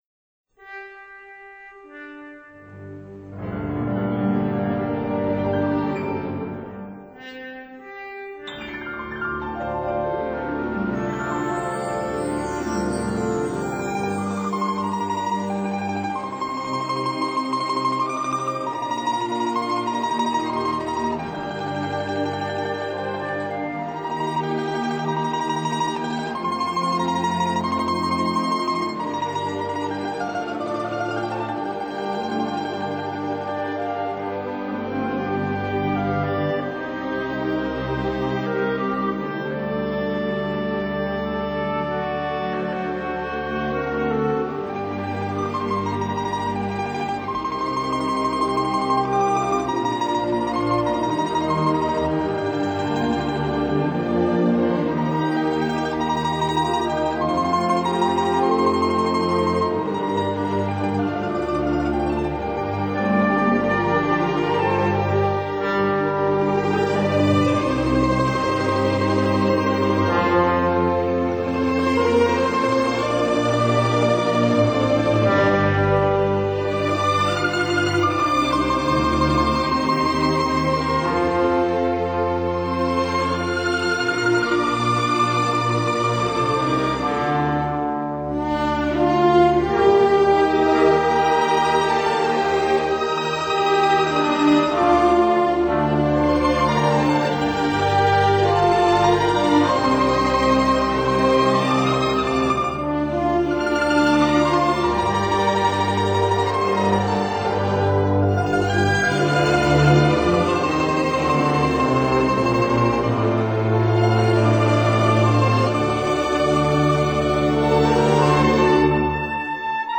最佳流行演奏唱片大奖